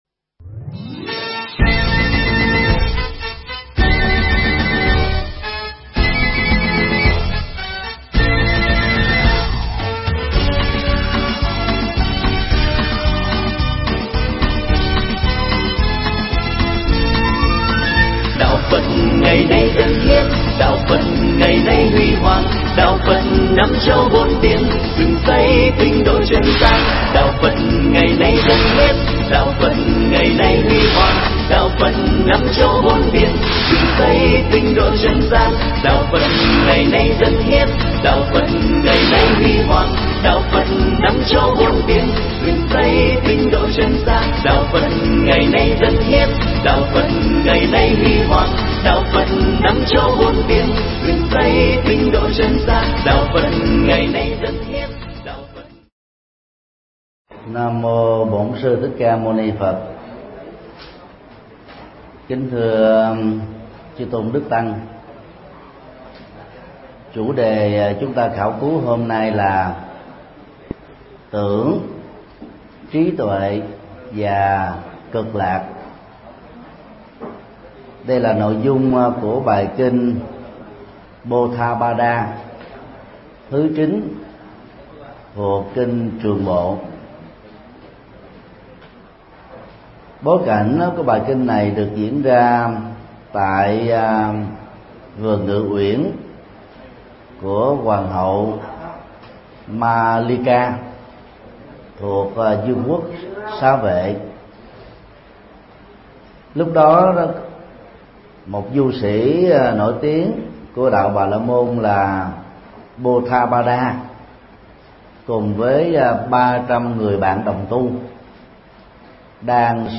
Mp3 Thuyết Pháp Kinh trường bộ 09 – Kinh Potthapada – Tưởng trí tuệ và cực lạc – Thầy Thích Nhật Từ Giảng tại chùa Tường Vân, Bình Chánh, ngày 06/06/2014